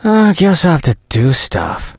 sandy_start_vo_03.ogg